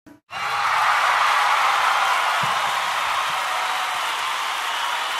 歓声
歓声効果音.mp3